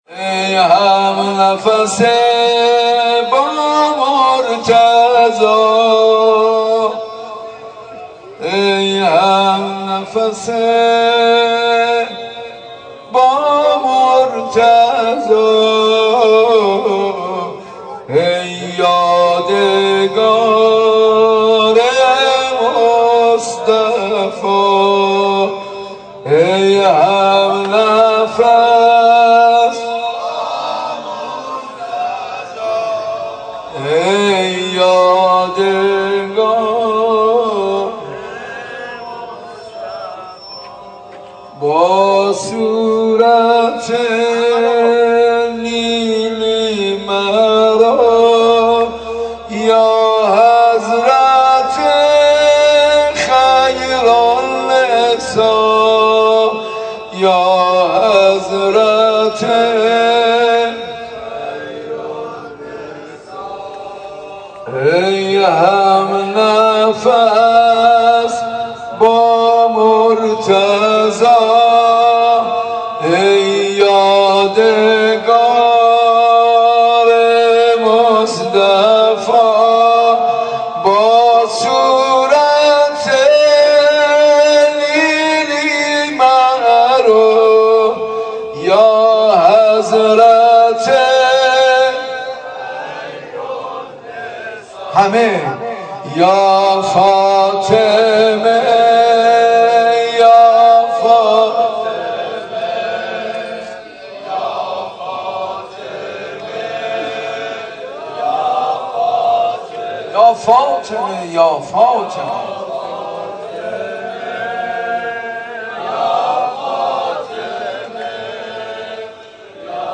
روضه خوانی حاج منصور ارضی
مداحی حاج منصور ارضی